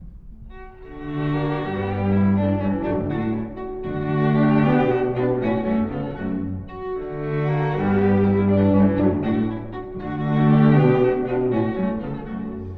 ↑古い録音のため聴きづらいかもしれません！（以下同様）
Allegro – Minore
スケルツォ（＝冗談、きまぐれ）風の速い楽章です。
弾むようなリズムが終始続きます。
ただし、転調が多く、途中でMinoreという暗くうごめくような部分もあるため、飽きさせない作りになっています。
beethoven-sq3-3.mp3